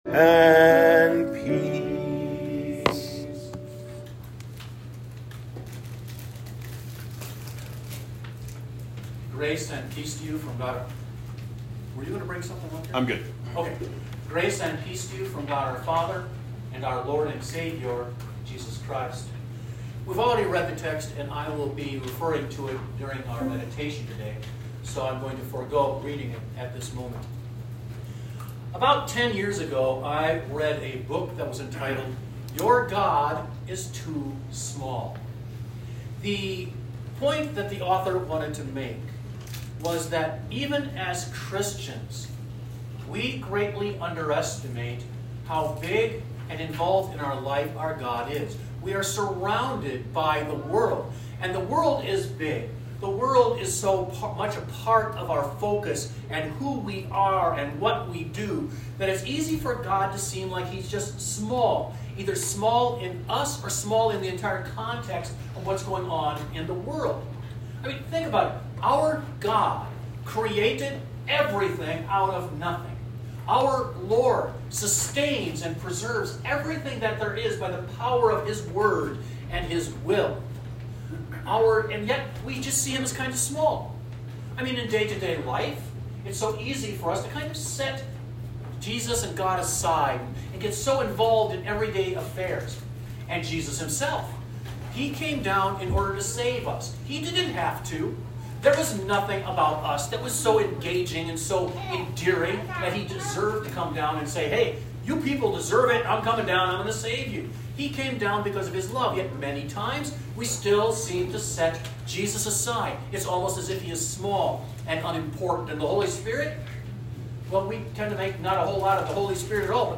Sermons | St John Evangelical Lutheran Church